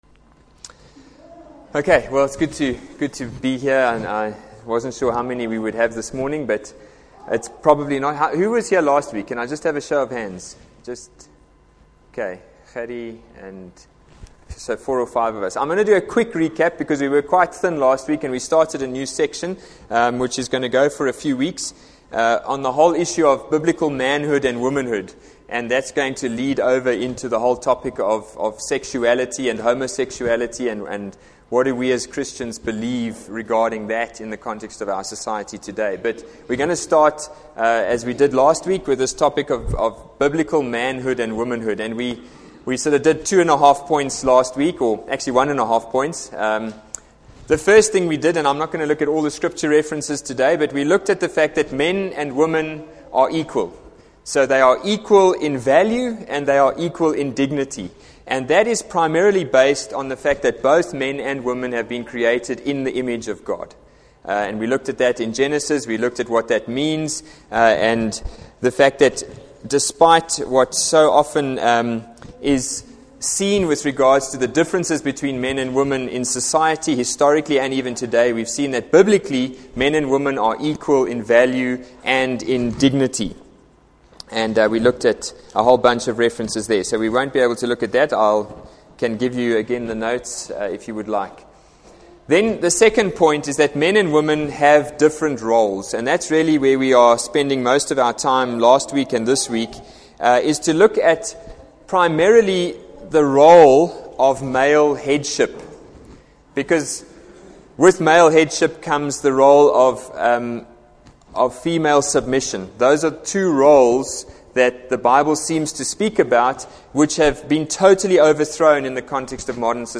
Adult Bible Class - Biblicle Manhood Womanhood - 2.mp3